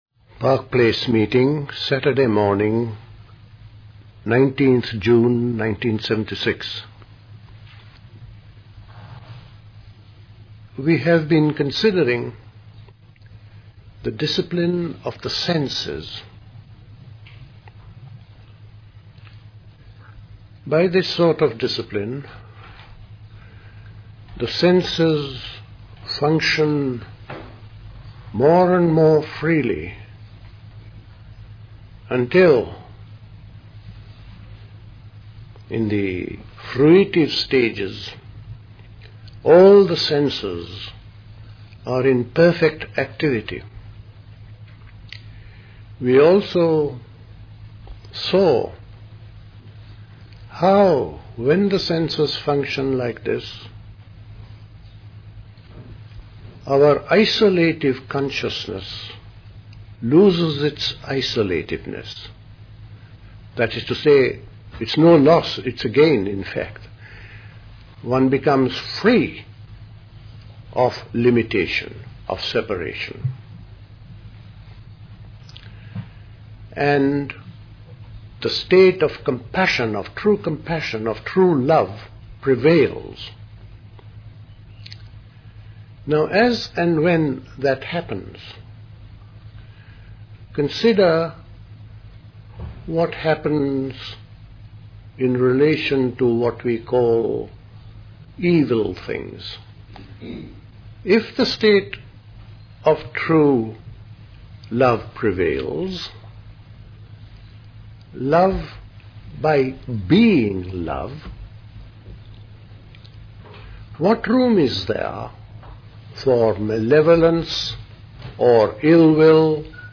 Recorded at the 1976 Park Place Summer School.